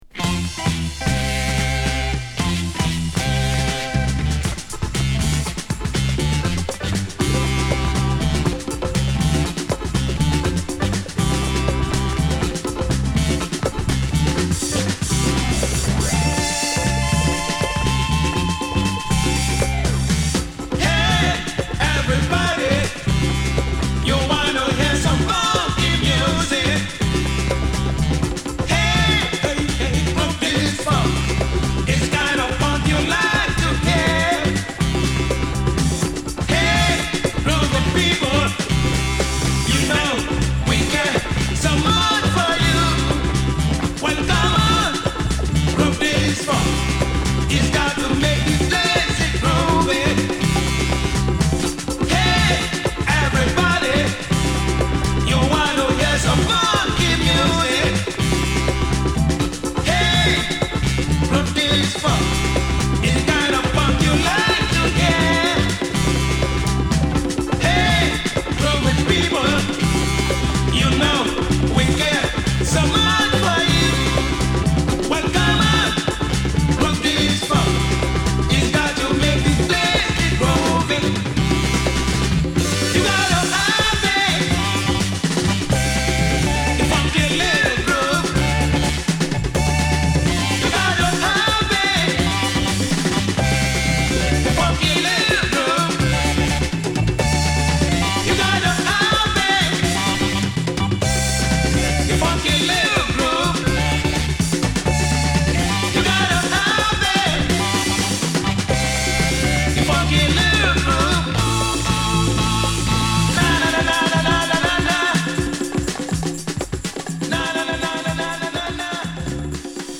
ファズのきいたギターと小気味よいパーカッション、ヘビーなビートが全編を支配する